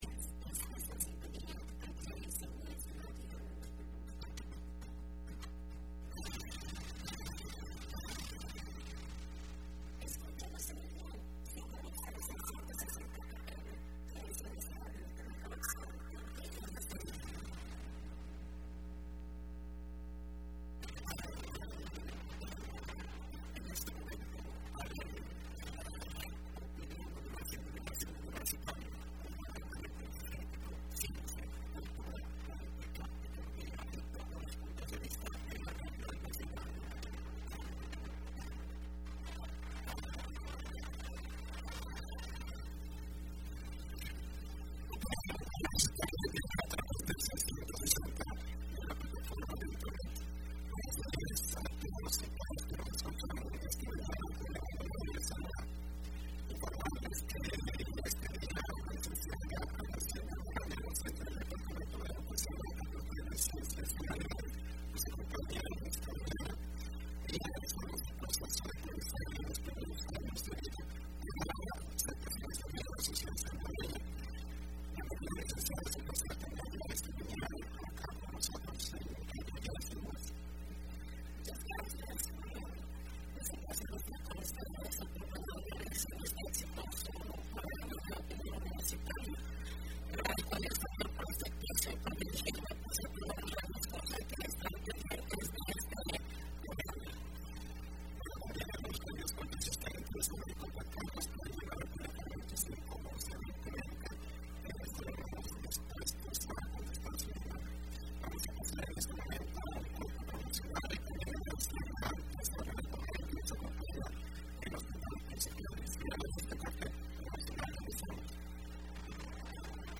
Entrevista Opinión Universitaria (3 Julio 2015 ): Proceso de aprendizaje en los primeros años de vida para ser mejores ciudadanos.